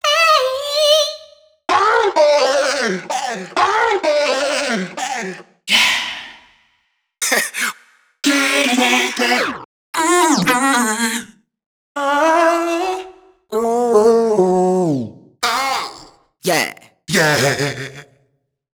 VOCALS.wav